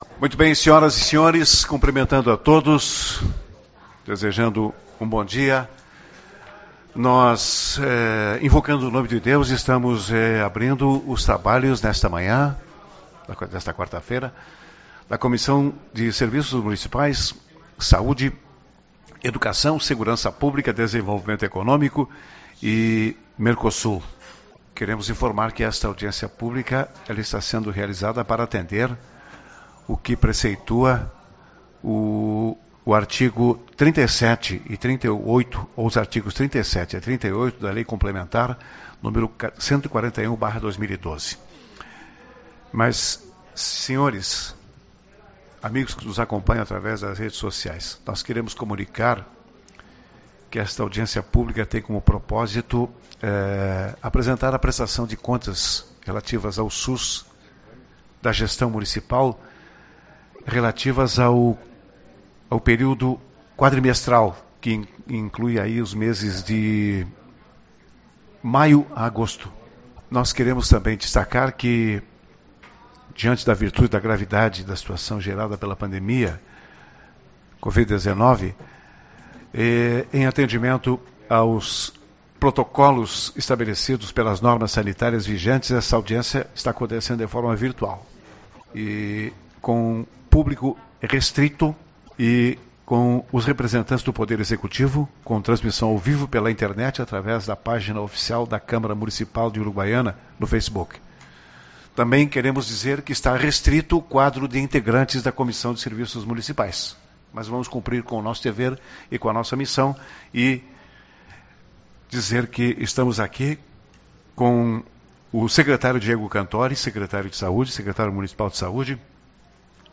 29/09 - Audiência Pública-Prest. Ctas do SUS